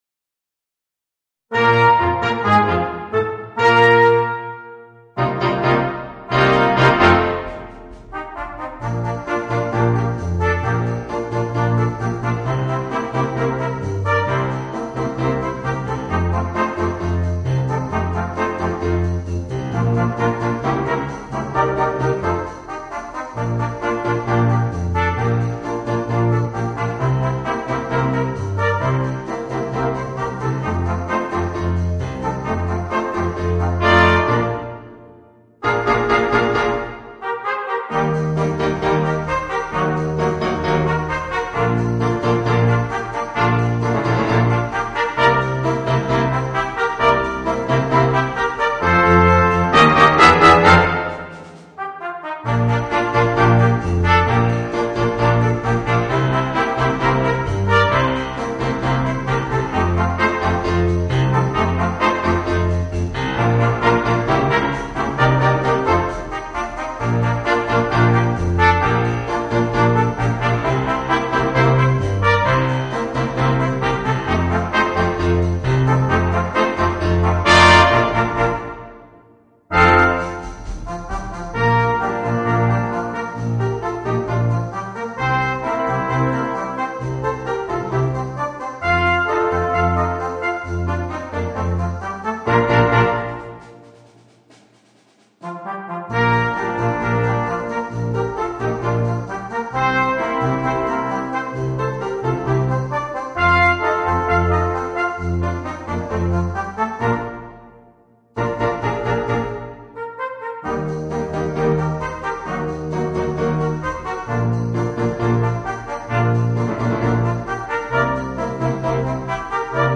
Voicing: 5 - Part Ensemble and Piano / Keyboard